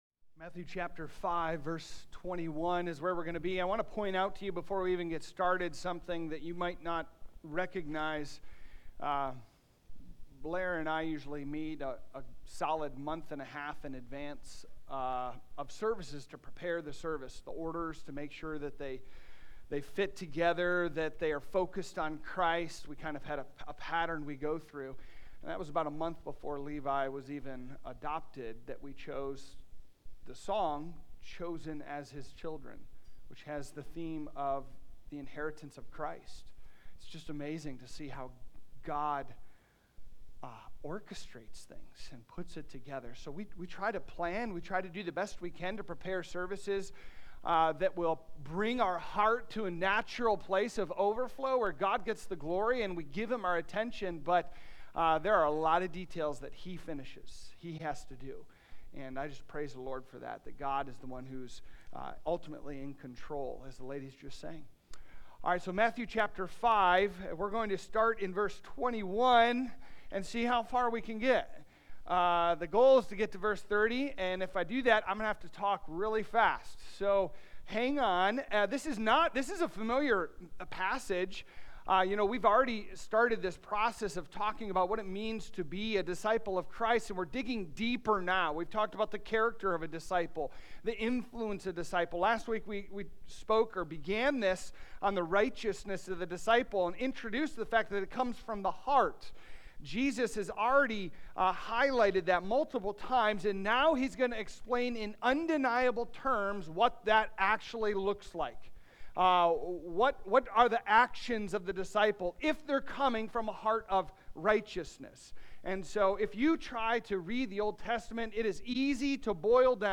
Sermons | Trinity Baptist Church